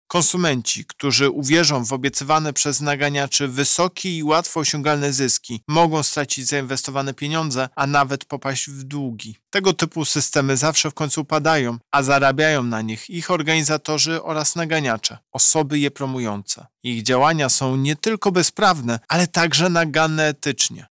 To nieuczciwa praktyka rynkowa, która może skutkować poważnymi stratami finansowymi, a niekiedy ludzkimi tragediami – wyjaśnia prezes Urzędu Ochrony Konkurencji i Konsumenta Tomasz Chróstny: